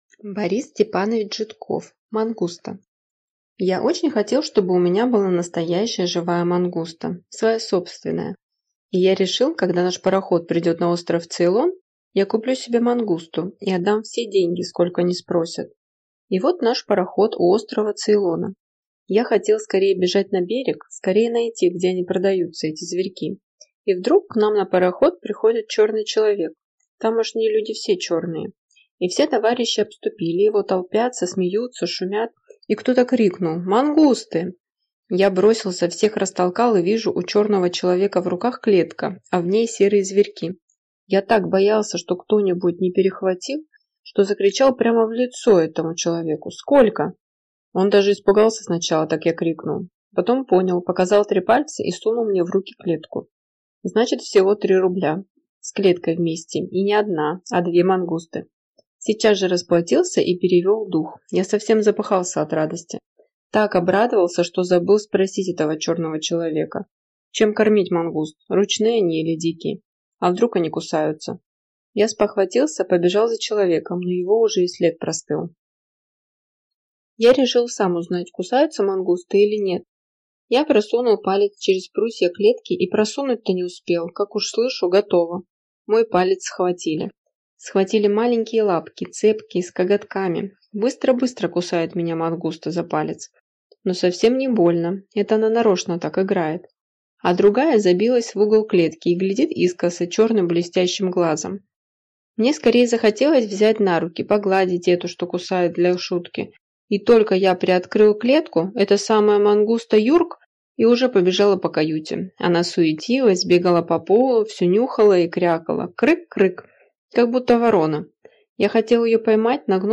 Аудиокнига Мангуста | Библиотека аудиокниг